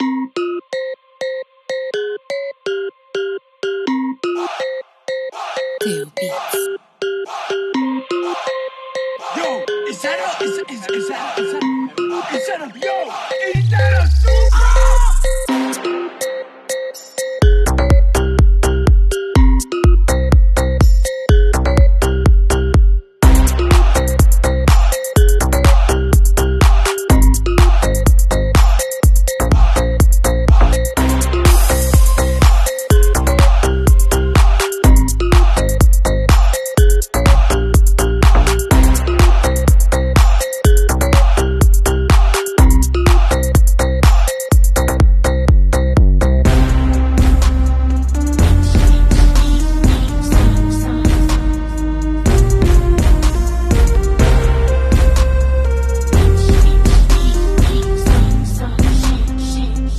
1000BHP TOYOTA SUPRA MK4 WITH Sound Effects Free Download